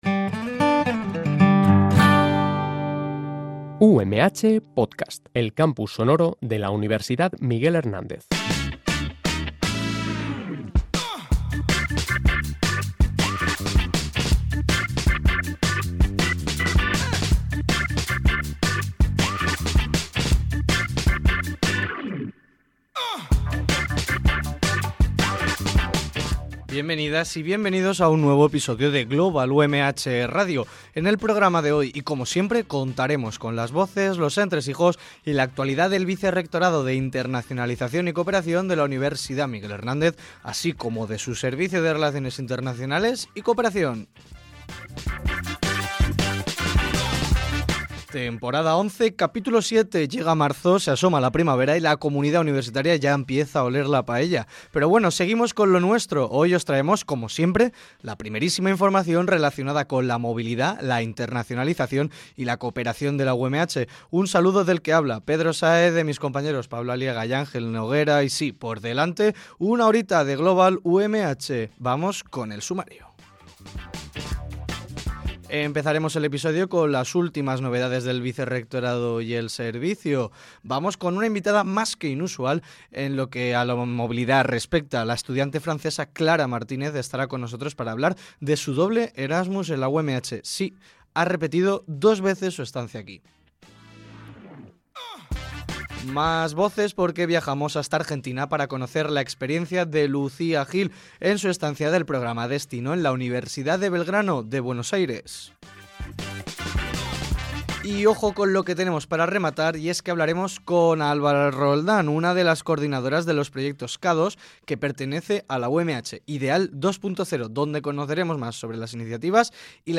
En el programa de hoy, el séptimo de la undécima temporada, tendremos un amplio abanico de voces que completan la actividad del Vicerrectorado de Internacionalización y Cooperación de la UMH.